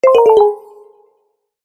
Error App Sound Effect Free Download
Error App